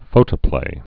(fōtə-plā)